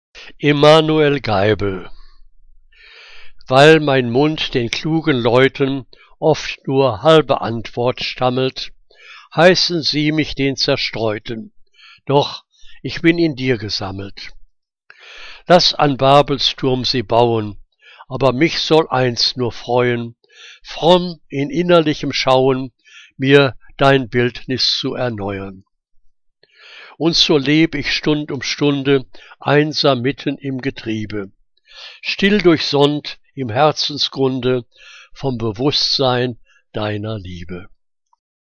Liebeslyrik deutscher Dichter und Dichterinnen - gesprochen (Emanuel Geibel)